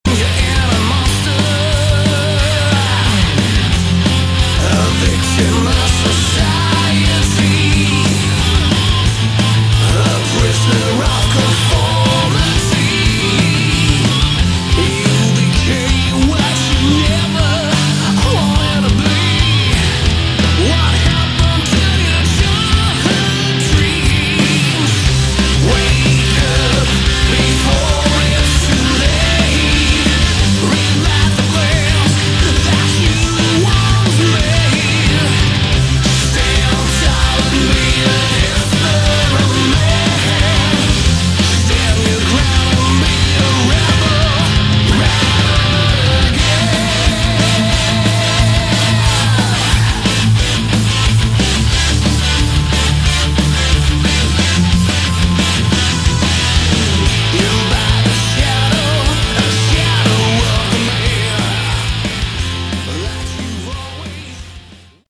Category: Hard Rock
Vox
Guitars, Bass, Keyboards
Drums